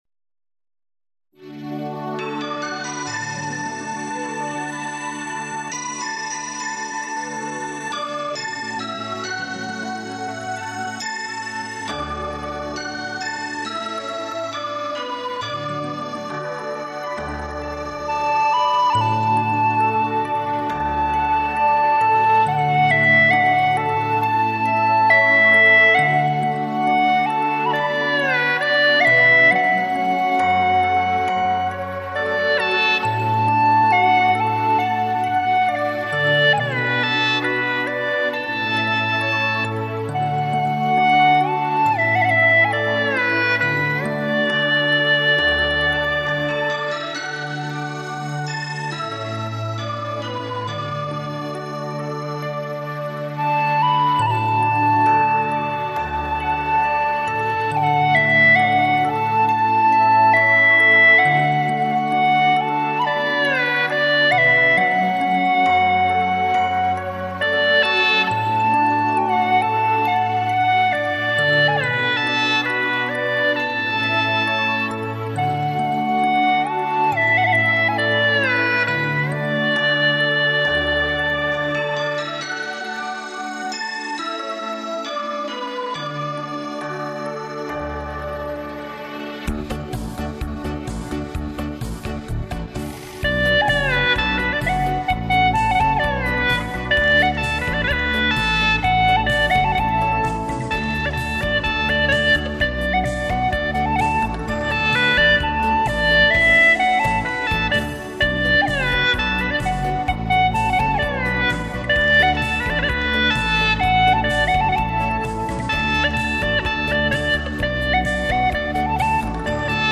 调式 : D 曲类 : 民族
傣味作品。